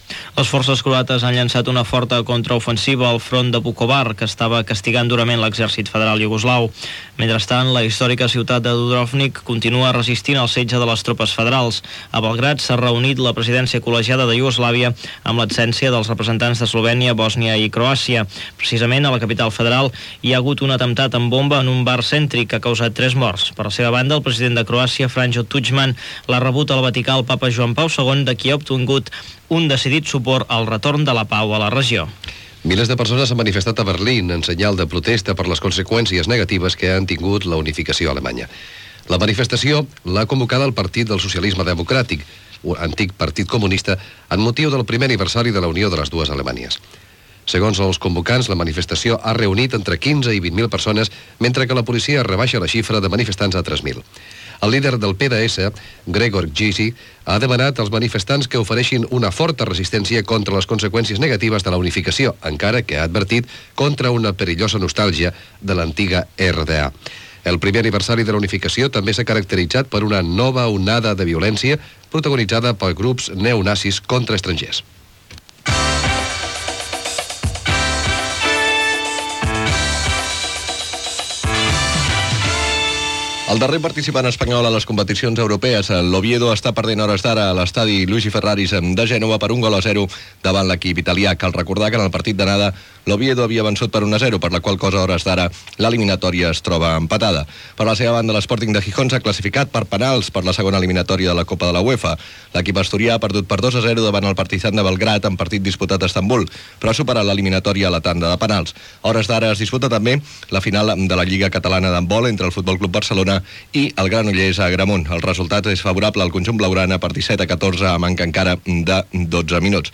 Notícies internacionals, esports, careta de sortdia
Informatiu
FM